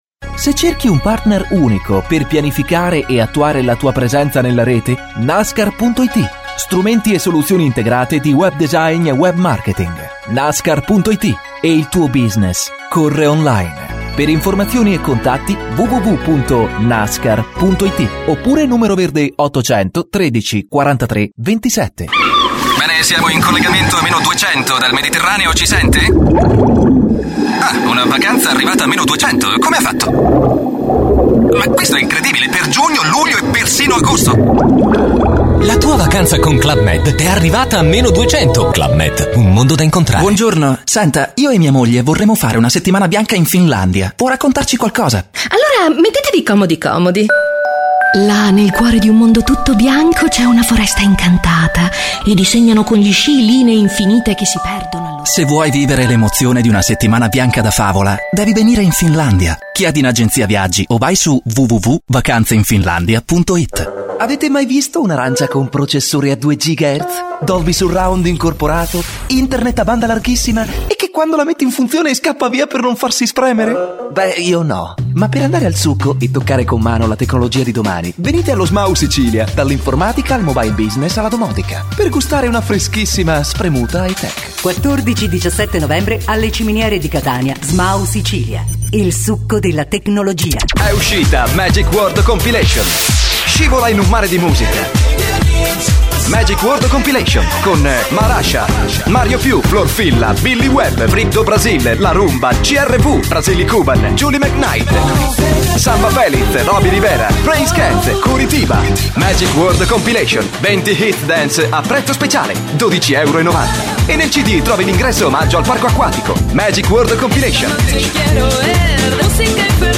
Masculino
Demo spot commercial